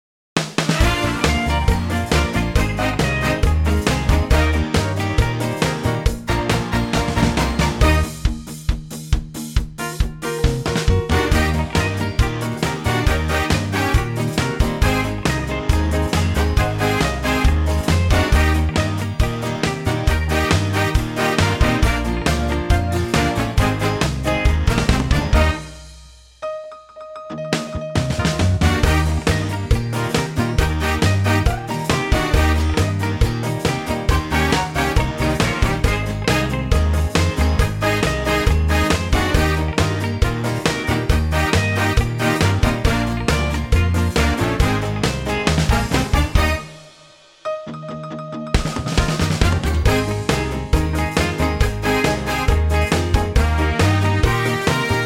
Unique Backing Tracks
key Eb 2:12
key - Eb - vocal range - Bb to Bb
Super fresh arrangement